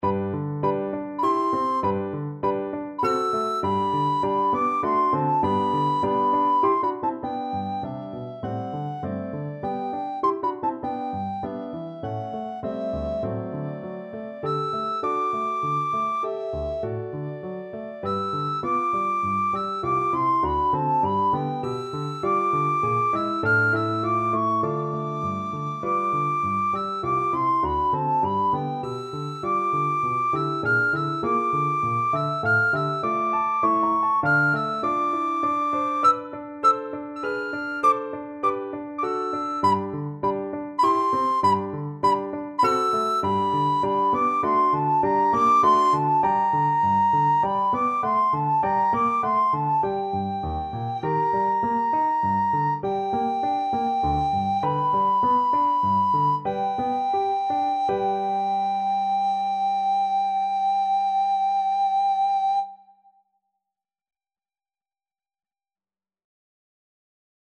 Free Sheet music for Soprano (Descant) Recorder
Recorder
G major (Sounding Pitch) (View more G major Music for Recorder )
3/4 (View more 3/4 Music)
D6-F7
~ = 100 Allegretto grazioso (quasi Andantino) (View more music marked Andantino)
Classical (View more Classical Recorder Music)